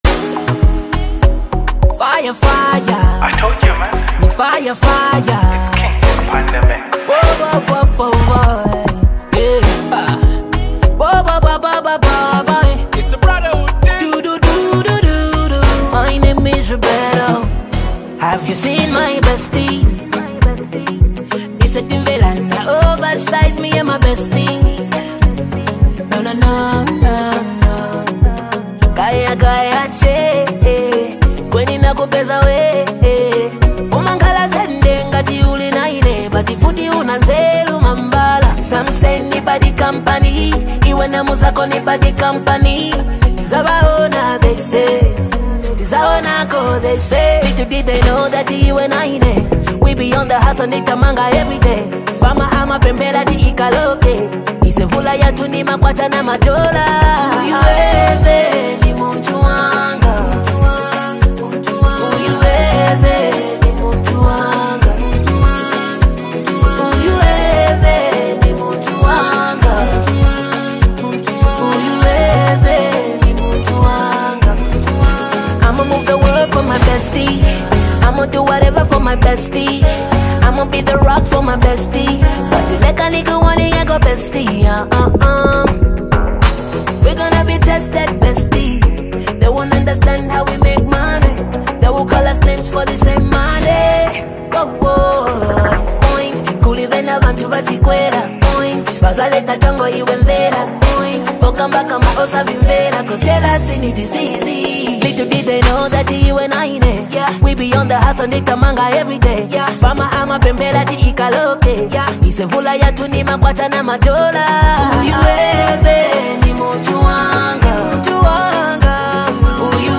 a love song celebrating deep connections in relationships.